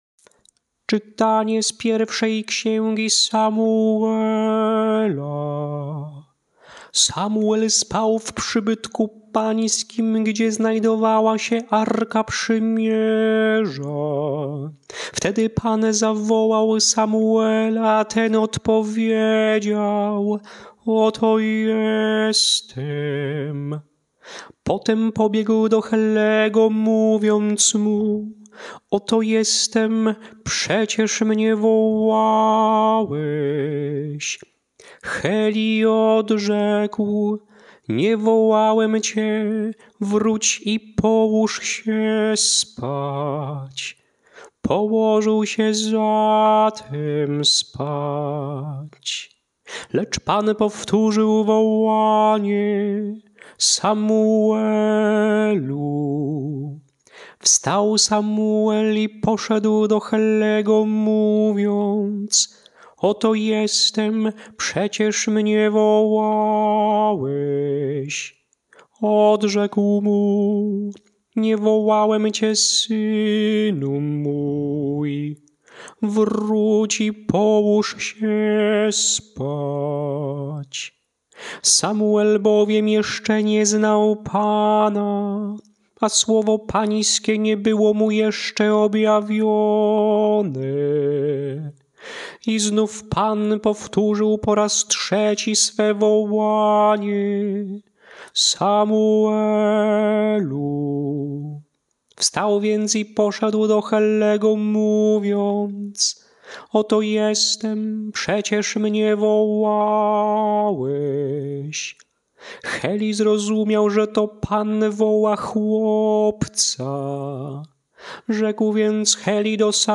Śpiewane lekcje mszalne – II Niedziela Zwykła
Melodie lekcji mszalnych przed Ewangelią na II Niedzielę Zwykłą: